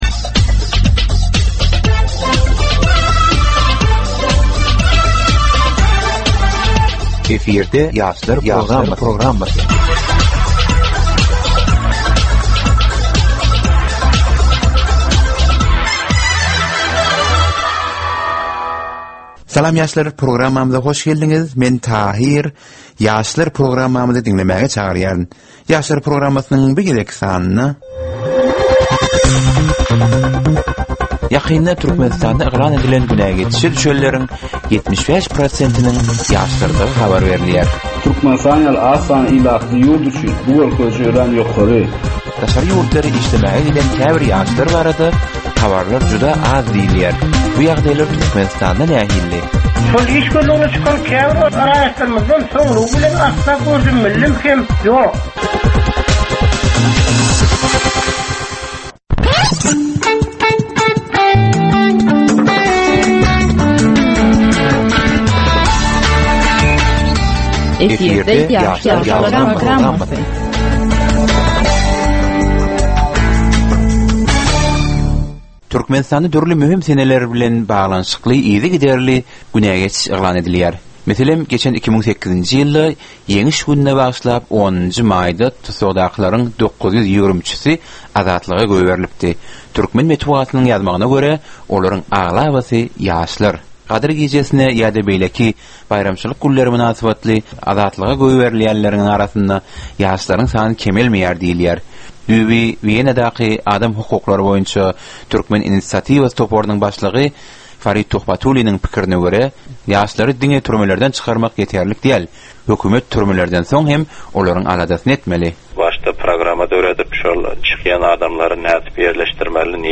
Türkmen we halkara yaşlarynyň durmuşyna degişli derwaýys meselelere we täzeliklere bagyşlanylyp taýýarlanylýan 15 minutlyk ýörite geplesik. Bu gepleşikde ýaşlaryň durmuşyna degişli dürli täzelikler we derwaýys meseleler barada maglumatlar, synlar, bu meseleler boýunça adaty ýaşlaryň, synçylaryň we bilermenleriň pikirleri, teklipleri we diskussiýalary berilýär. Gepleşigiň dowamynda aýdym-sazlar hem eşitdirilýär.